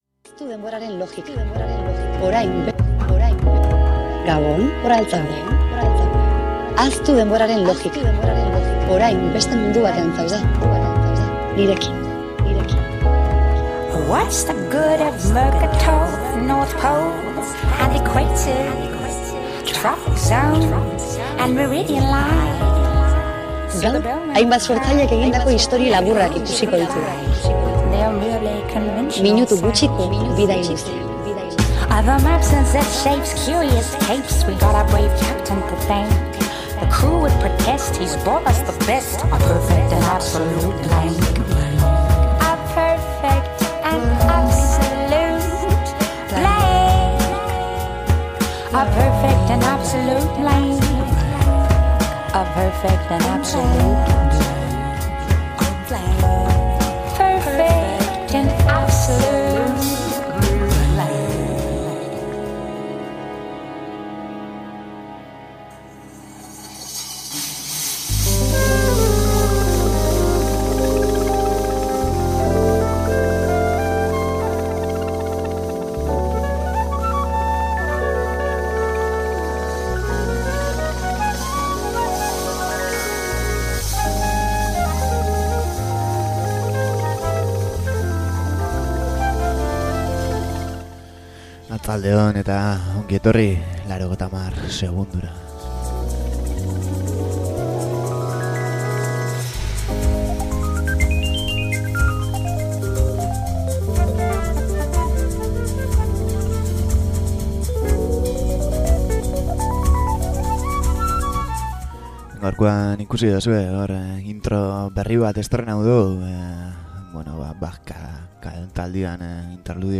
Etxean, manta azpian, goxo goxo jarri eta begiak itxita entzuteko beste irratsaio bat. Lasaitasunean jarraitzen dugu…